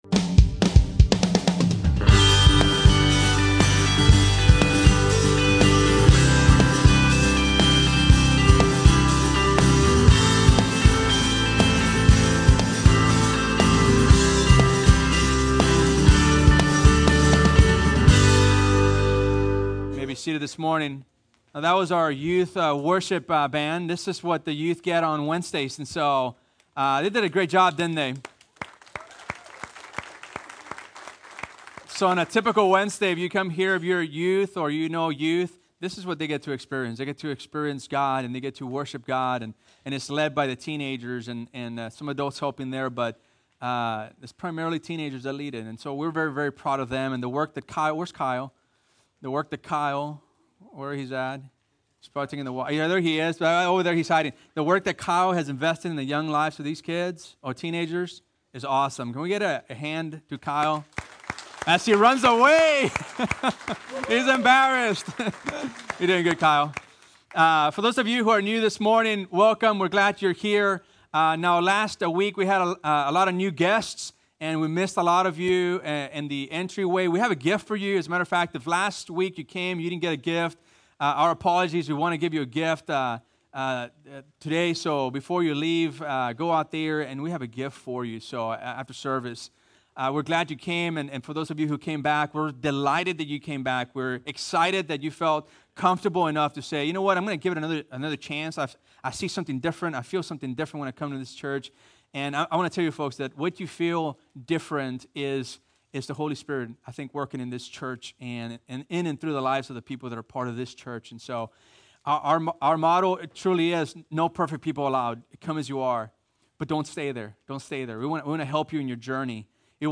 Two Rivers Bible Church - Sermons